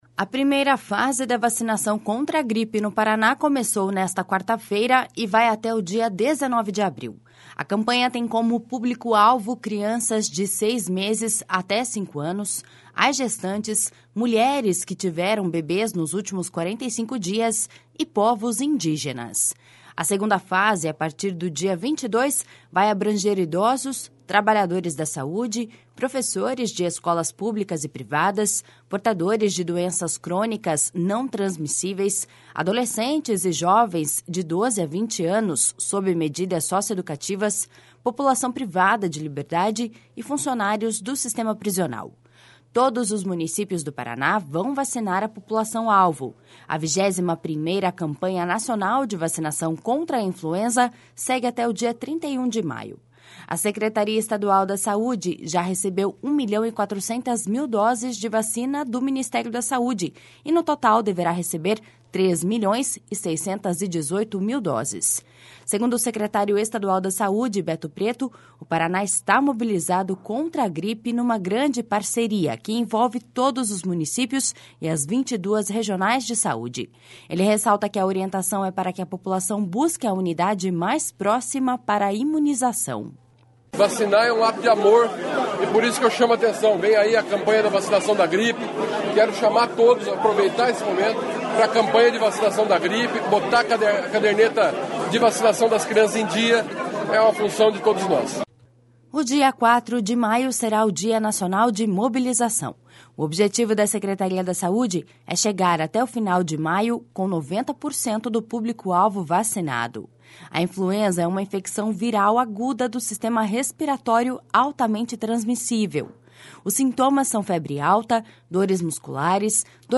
Ele ressalta que a orientação é para que a população busque a unidade mais próxima para a imunização.// SONORA BETO PRETO.// O dia 4 de maio será o Dia Nacional de Mobilização.